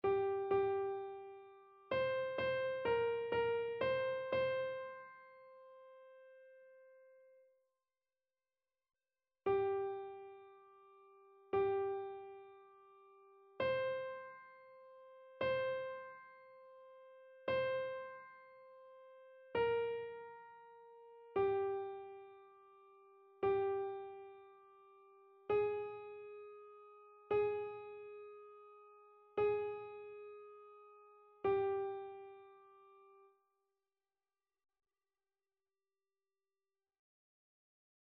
Chœur
annee-c-temps-ordinaire-20e-dimanche-psaume-39-soprano.mp3